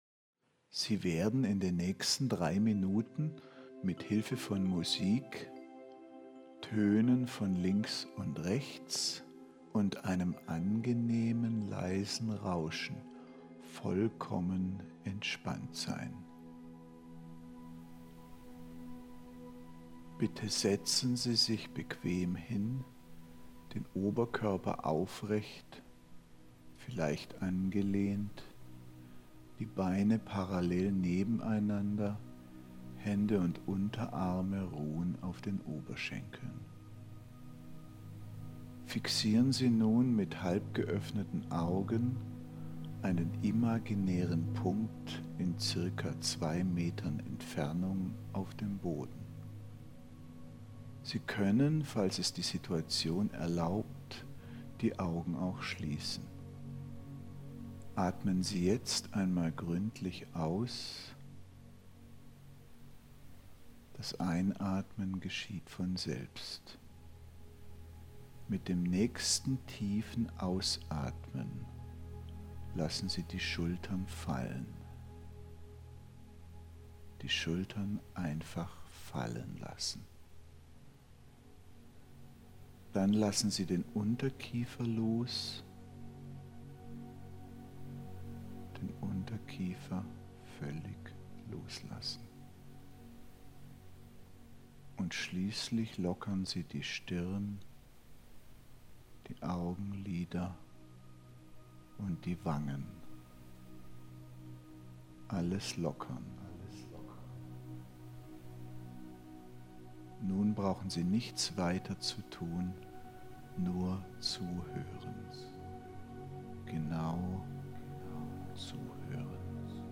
Die Hypnose mit bilateraler Gehirnstimulation, ist ein anerkanntes Verfahren der Kurzzeittherapie – und unterstützt in Kombination mit dem Buch wunderbar auf dem Weg zum Nichtraucher. Wechselseitige Töne von links und rechts versetzen das Gehirn in Entspannungs-, Motivations- und Veränderungsmodi.
Mini-Rauch-Stopp-Hypno.mp3